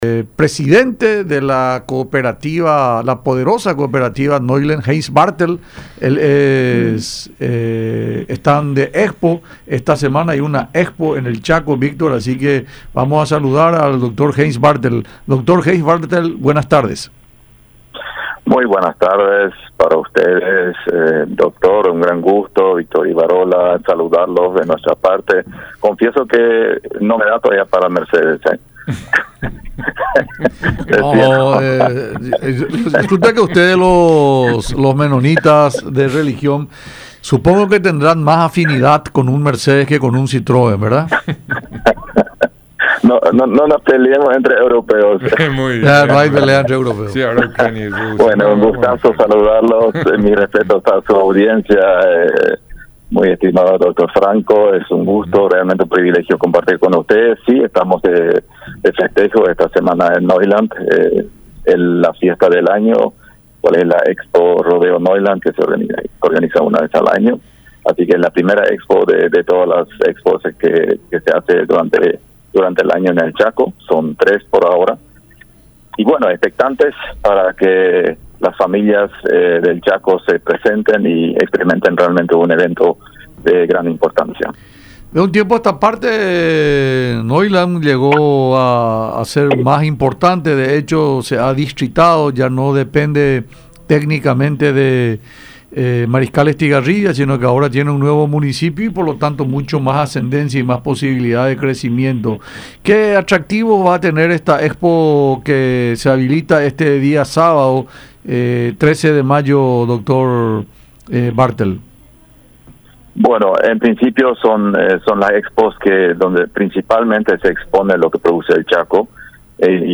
en diálogo con Francamente por Unión TV y radio La Unión.